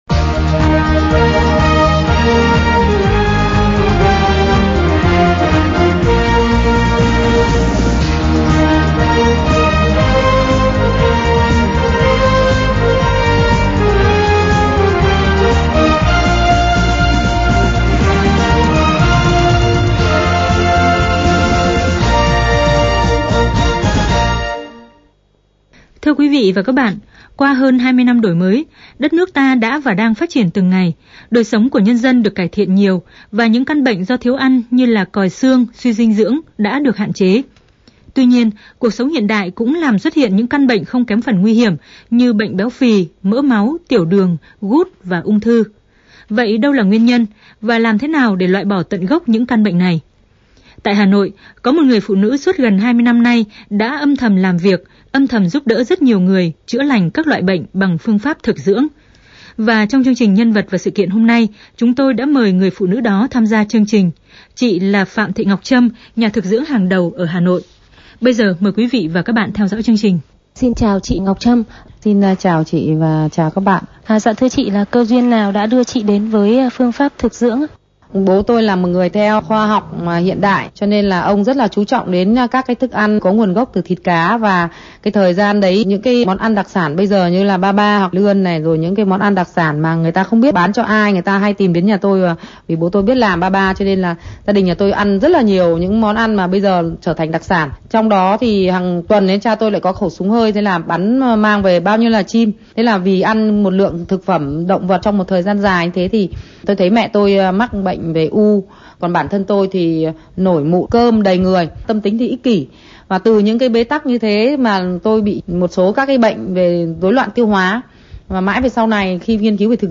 Đài phát thanh tiếng nói Việt Nam phỏng vấn